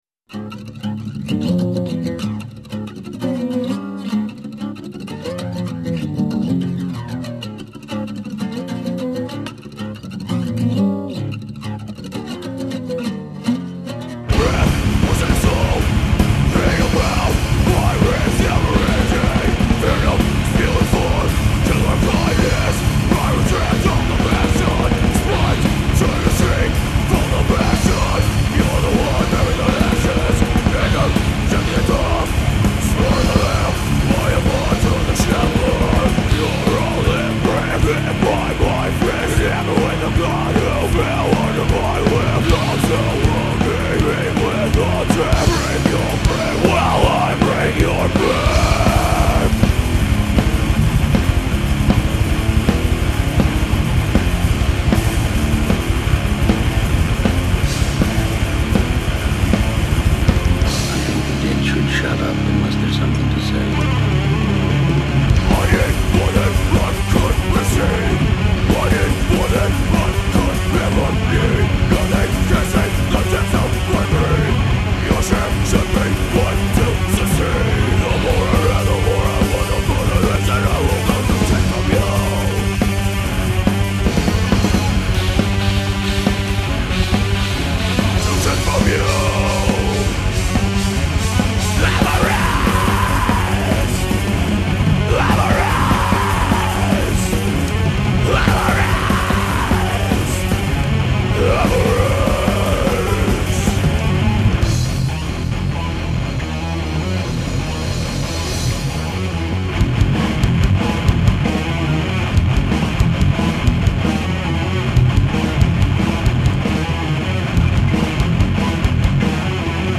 Hell, if you dig metal, check out these guys: